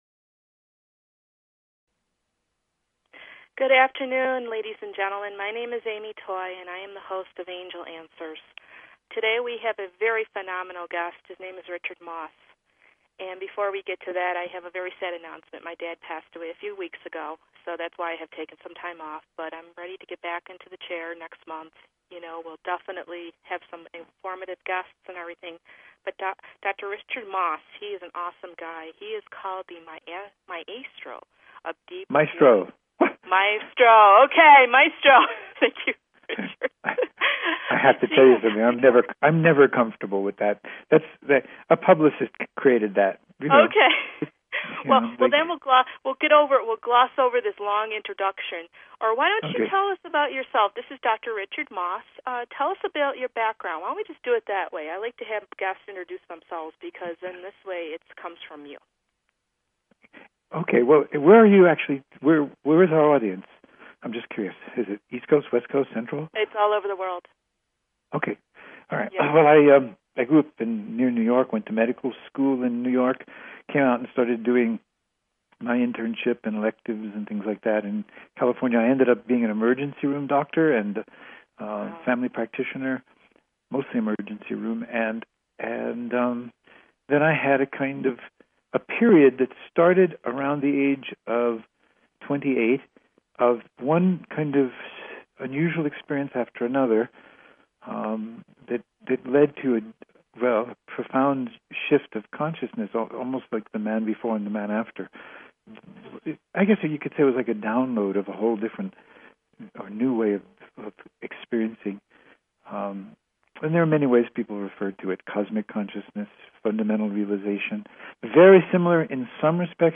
Talk Show Episode, Audio Podcast, Angel_Answers and Courtesy of BBS Radio on , show guests , about , categorized as